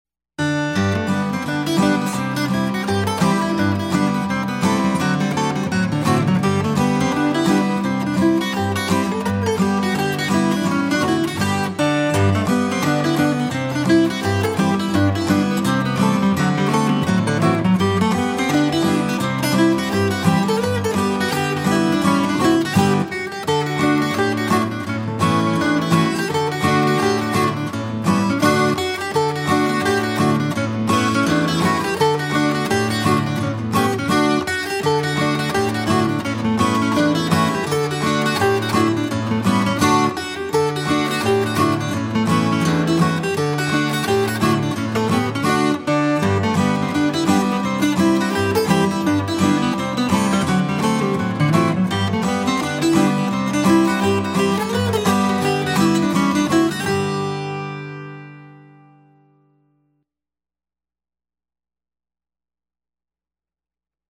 DIGITAL SHEET MUSIC - FLATPICK/PLECTRUM GUITAR SOLO
FLATPICK/PLECTRUM GUITAR SOLO Reel